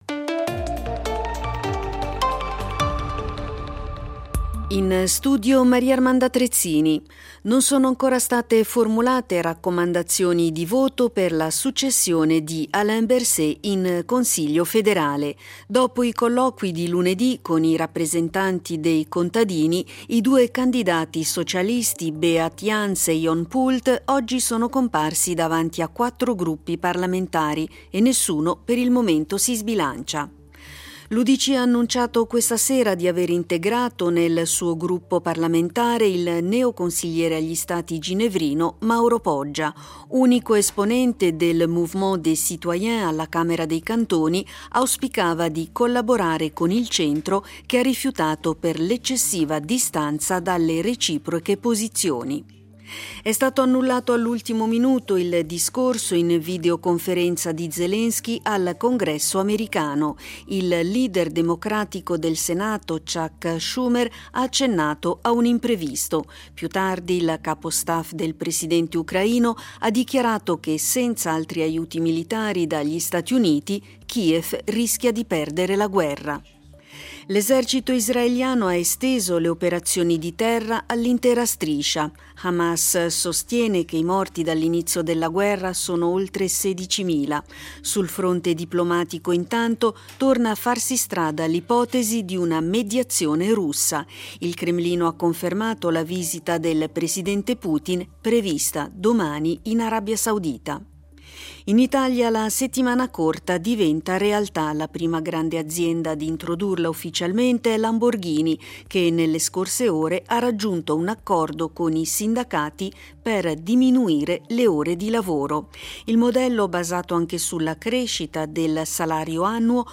Notiziario delle 23:00 del 05.12.2023